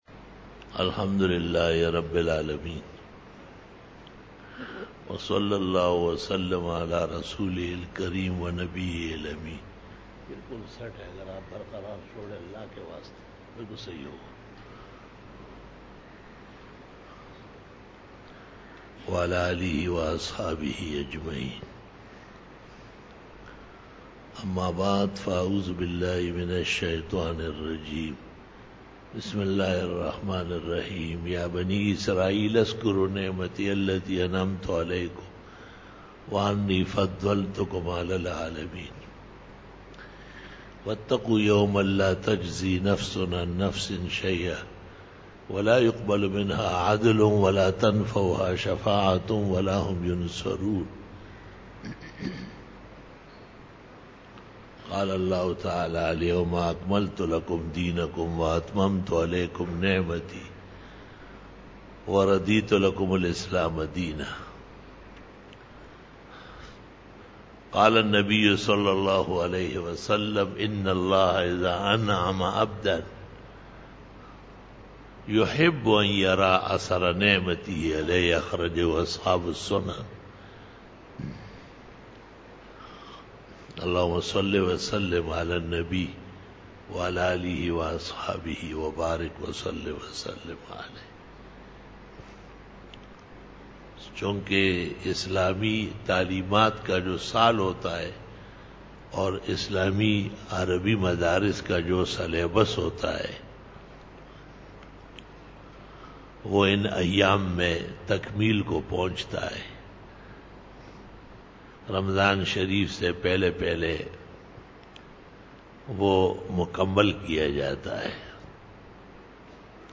13 BAYAN E JUMA TUL MUBARAK (30 MARCH 2018) (12 Rajab 1439H)
Khitab-e-Jummah 2018